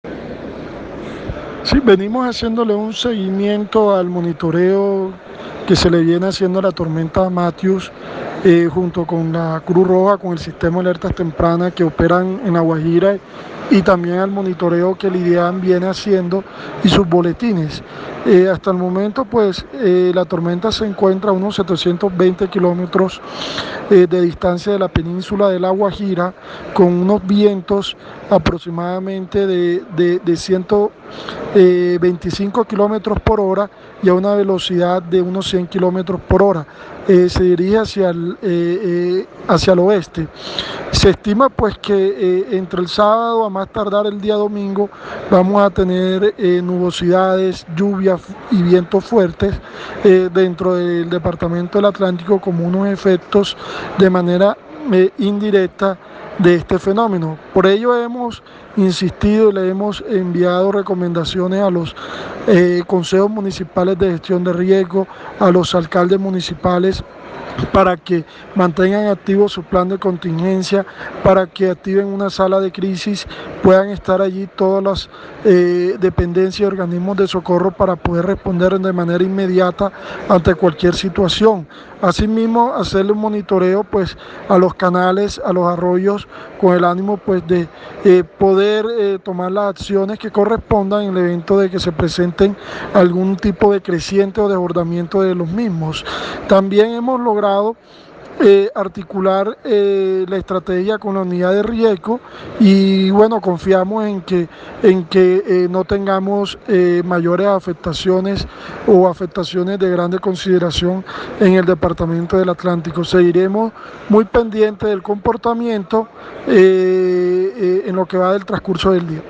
Audio-subsecretario-de-Gestión-de-Riesgo-de-Desastres-del-Atlántico2c-Edinson-Palma.mp3